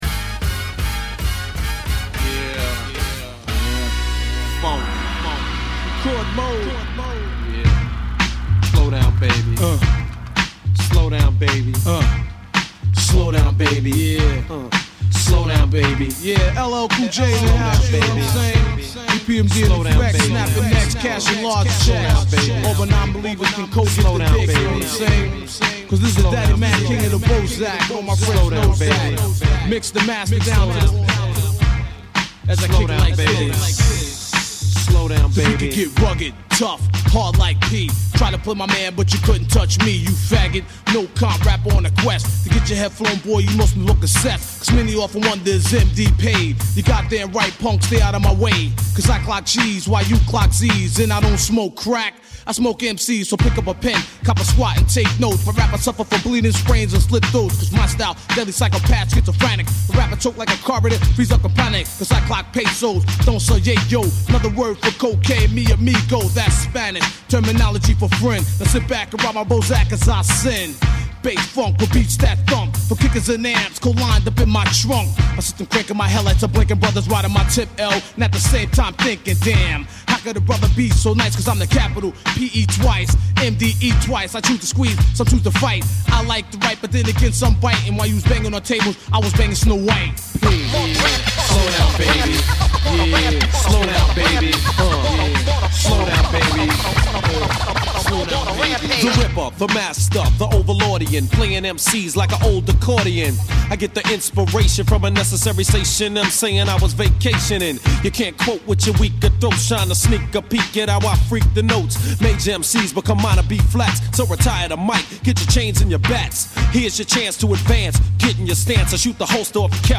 East coast hip-hop makes up the bulk of this mix
Hip Hop